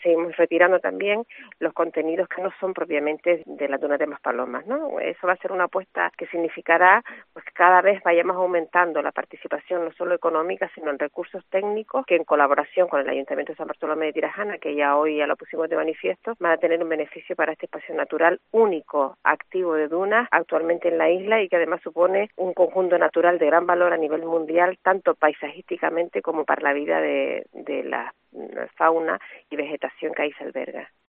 Inés Jiménez, consejera de Medio Ambiente del Cabildo de Gran Canaria